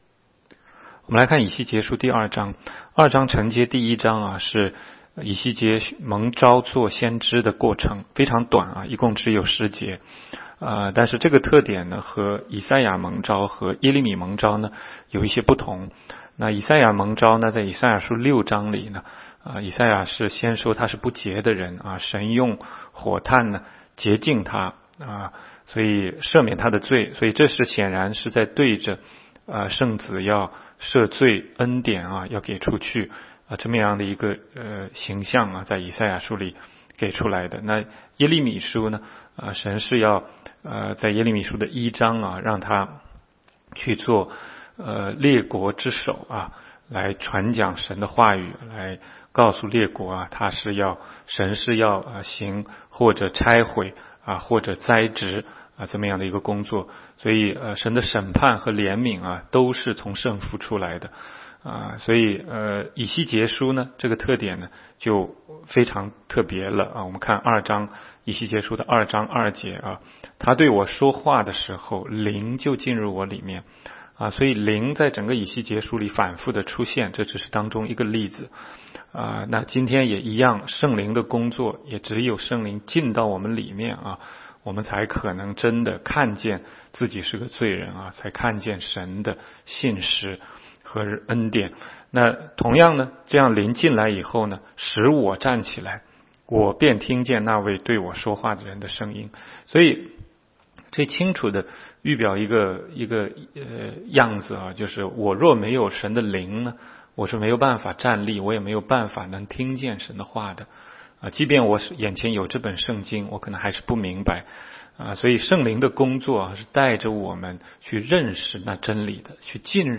16街讲道录音 - 每日读经 -《以西结书》2章